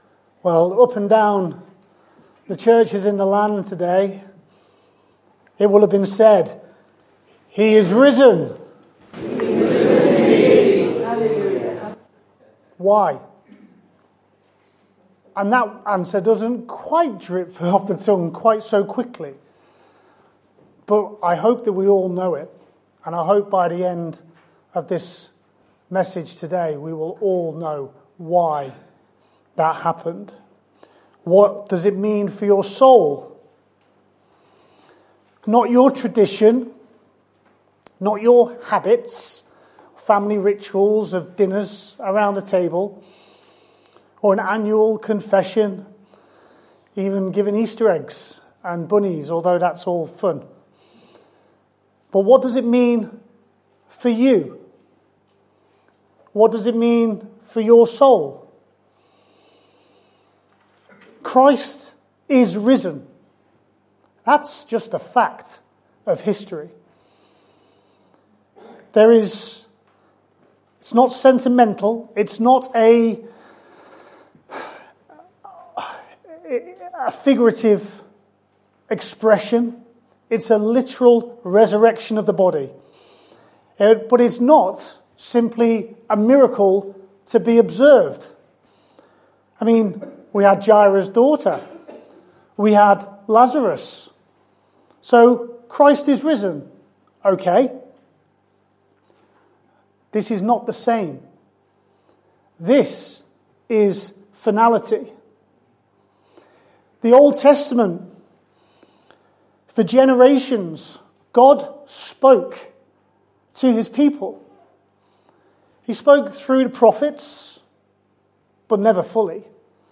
Occasional Sermons Passage: Hebrews 1:1-4 Service Type: Sunday Morning « Core Convictions Who is God and Who am I?